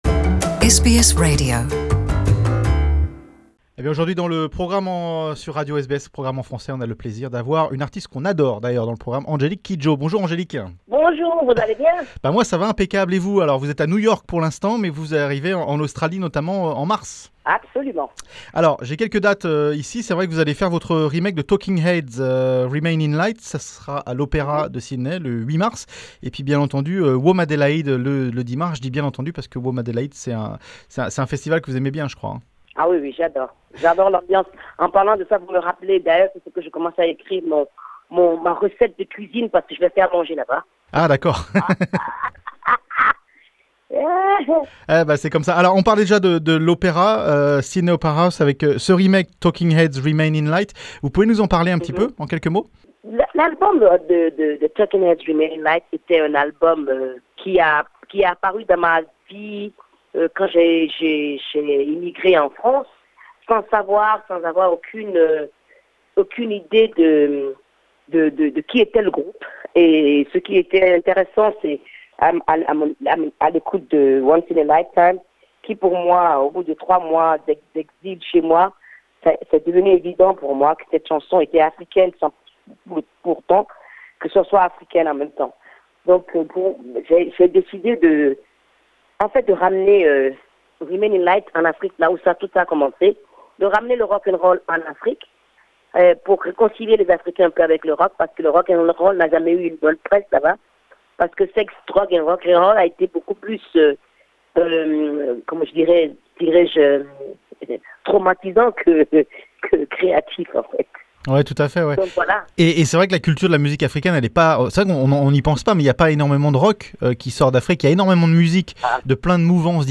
(Interview from January 1st, 2019) Share